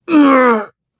Siegfried-Vox_Damage_03_b.wav